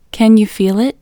LOCATE IN English Female 29